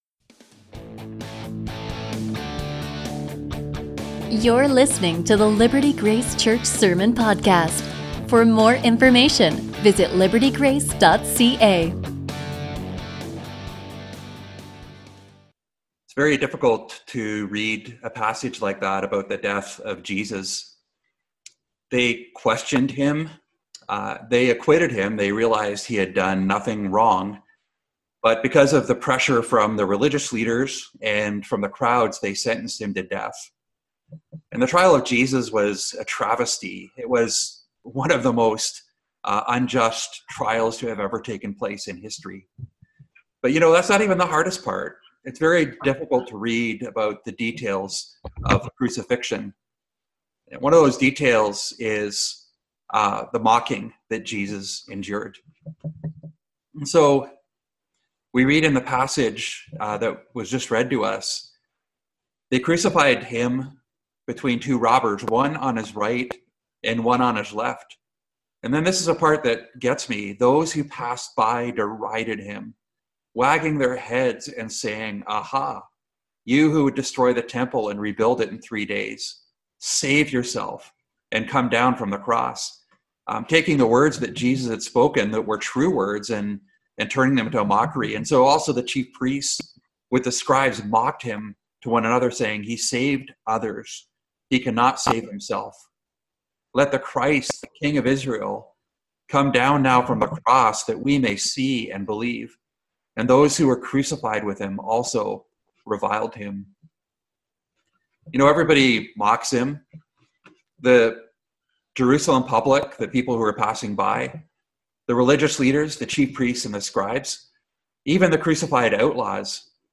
Sermons Mark Message